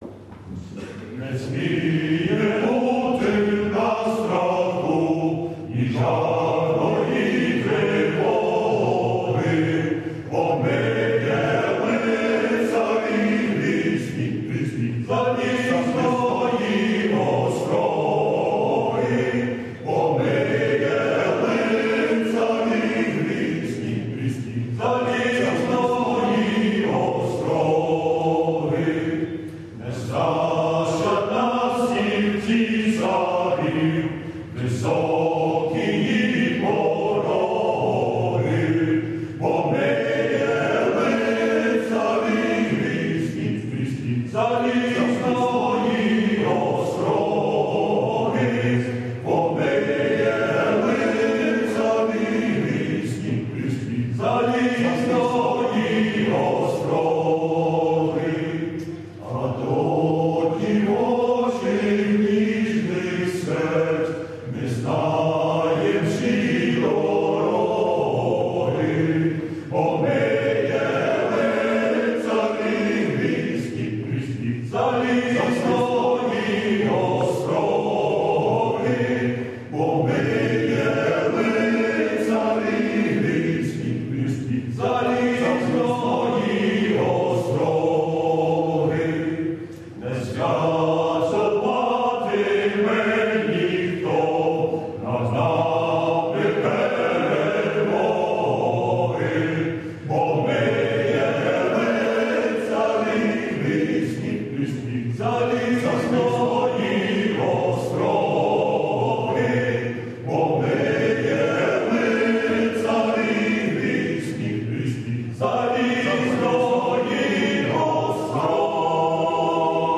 Heroes day Choir" Homin"